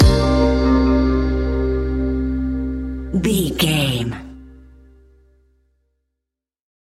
Ionian/Major
C♭
chilled
laid back
Lounge
sparse
chilled electronica
ambient
atmospheric